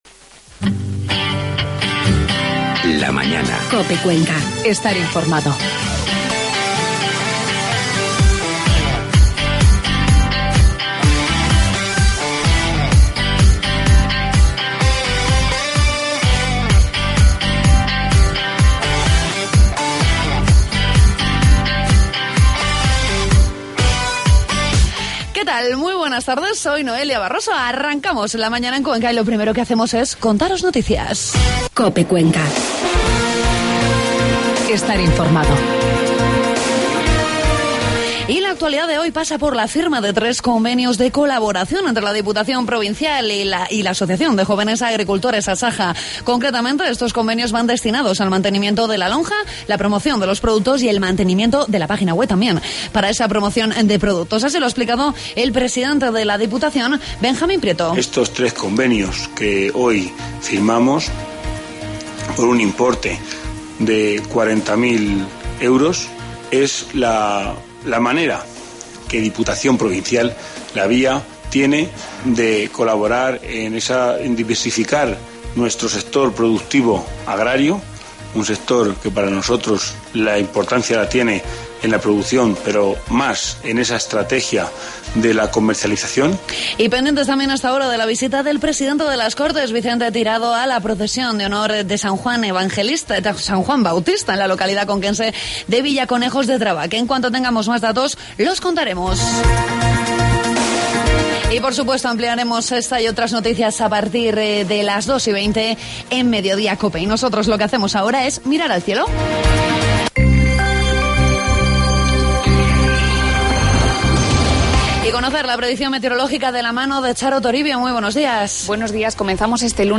Os ofrecermos un reportaje divulgativo sobre las Jorndas I+D que Enresa realizó los...